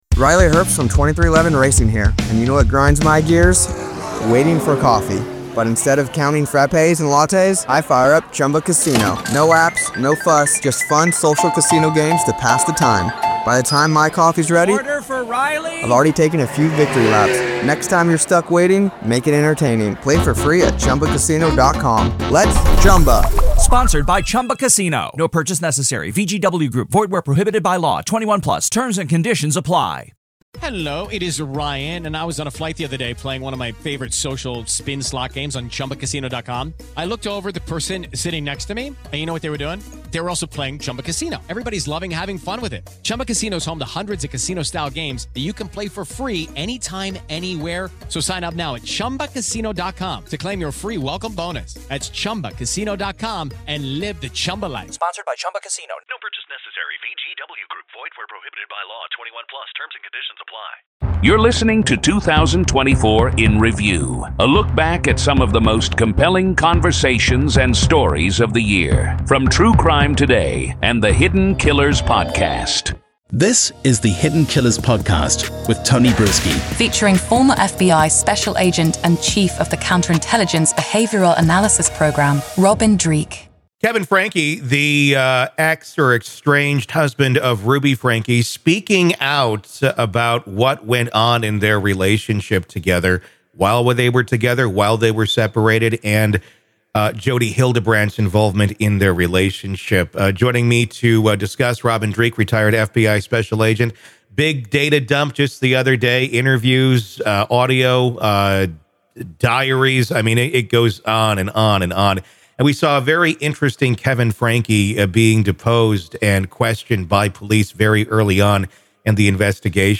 Throughout the past year, we've followed and dissected cases such as P. Diddy, Karen Read, Scott Peterson, Ruby Franke and Jodi Hildebrand, the Delphi Murders, Kouri Richins, Bryan Kohberger, Rex Heuermann, Alex Murdaugh, Chad and Lori Daybell, and the Adelson family. Each episode navigates through these stories, illuminating their details with factual reporting, expert commentary, and engaging conversation.